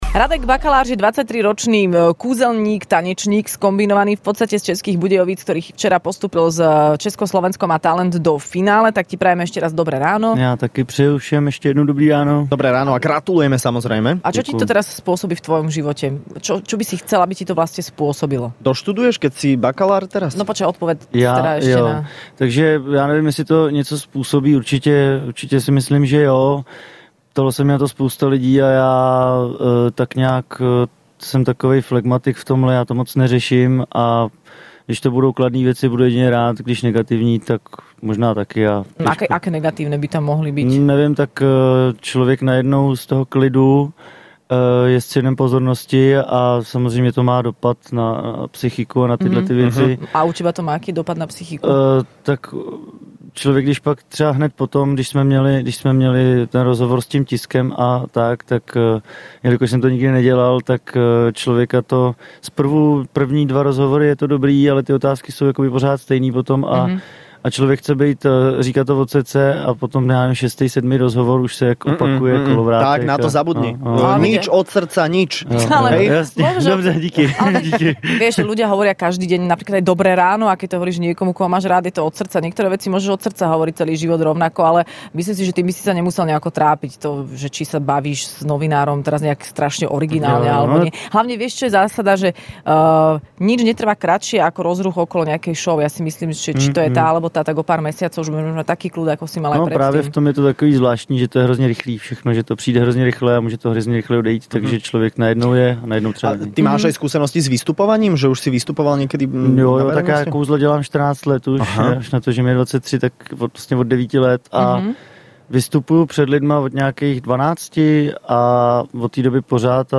Do Rannej šou prišli dvaja postupujúci semifinalisti zo šou Česko - Slovensko má talent